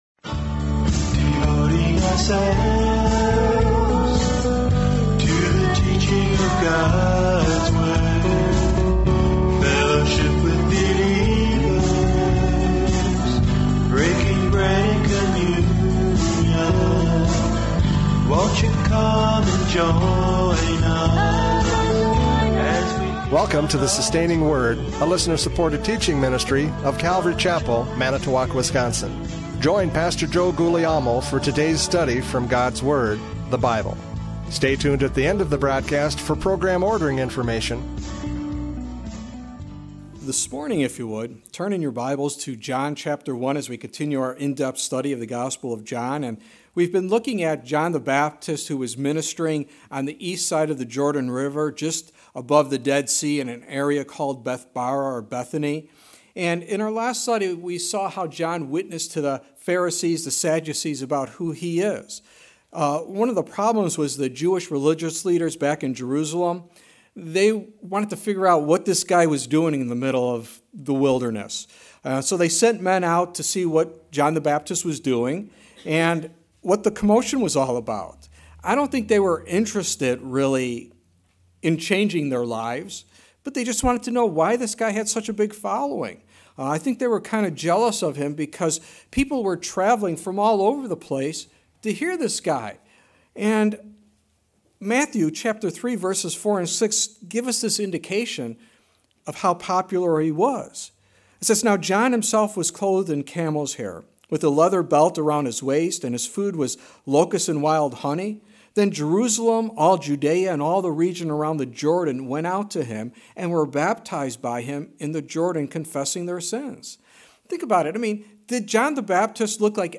John 1:29-34 Service Type: Radio Programs « John 1:19-28 Witness to the Religious!